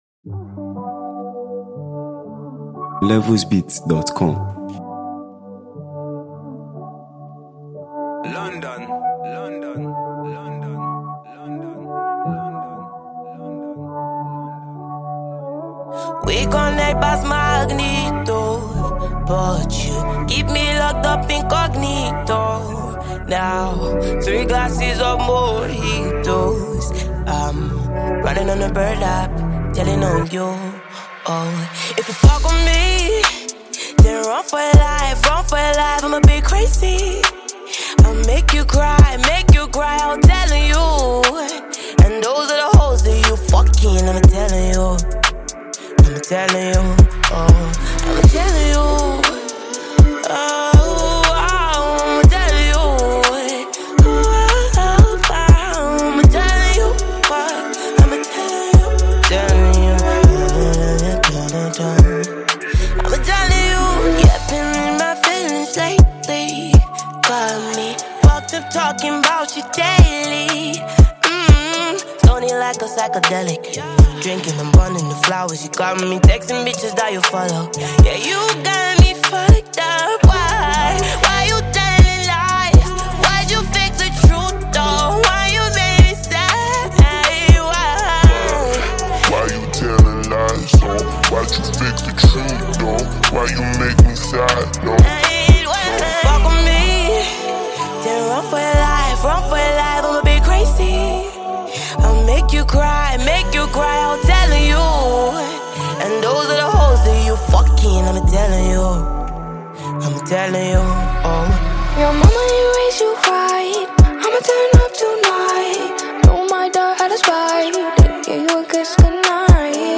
Nigeria Music 2025 3:17
silky vocals